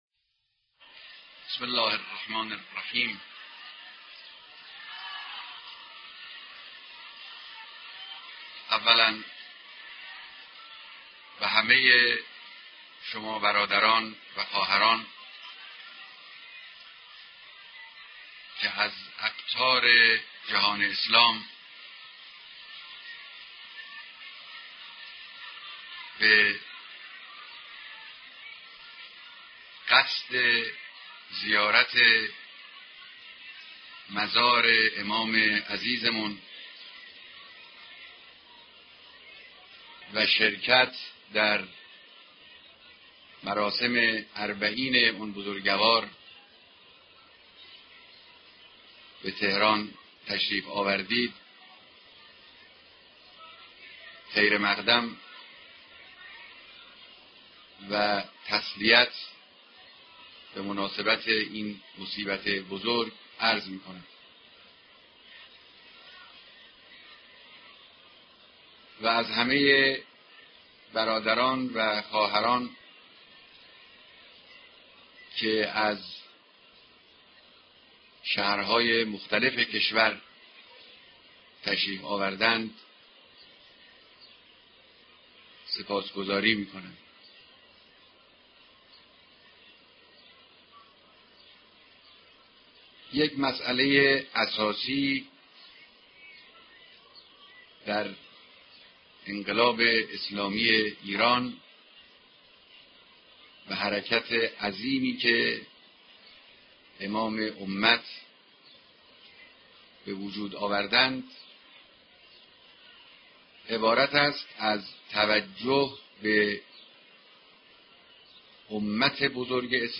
سخنرانی در مراسم چهلمین روز امام و بیعت میهمانان خارجی، مردم مشهد و عشایر استان خوزستان